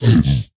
zpigangry2.ogg